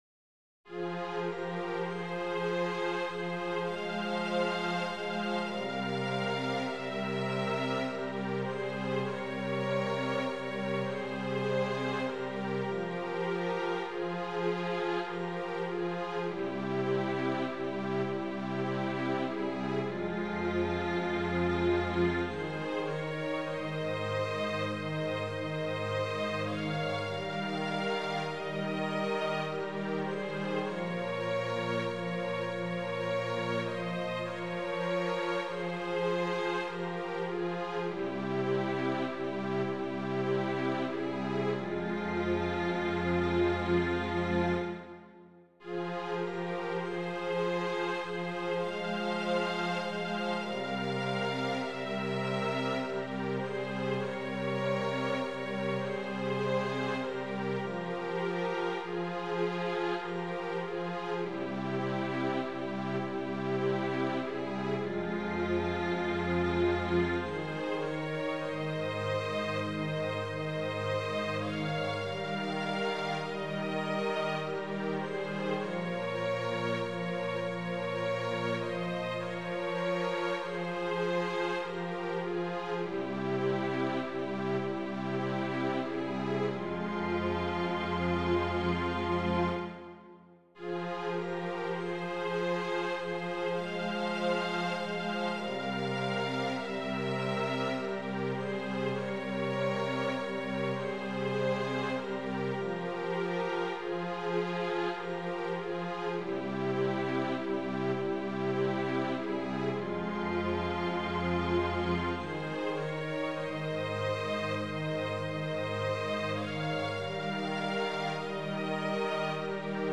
Number of voices: 4vv Voicing: SABB Genre: Sacred
Language: Latin Instruments: A cappella